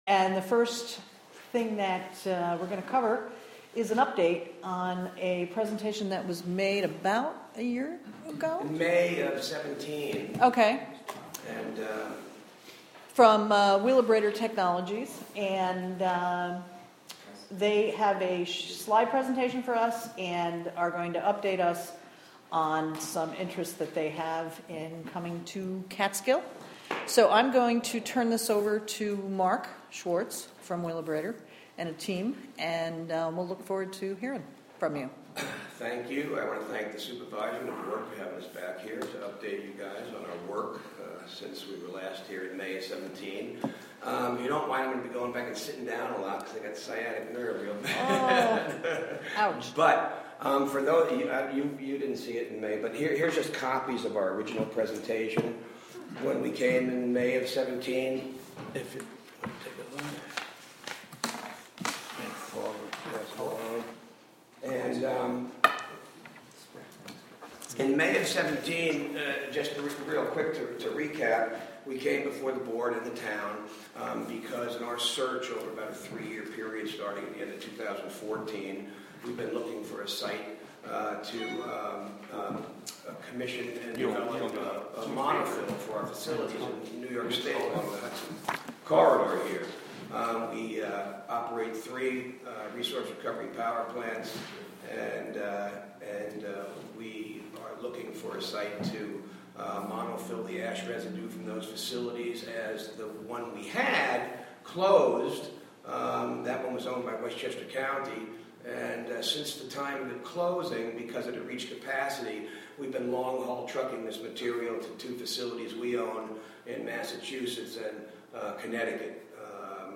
Catskill Town Board Committee Meeting: Jan 16, 2019: 6:30 pm
WGXC is pleased to partner with the town of Catskill to present live audio streams and subsequent audio archives of public meetings.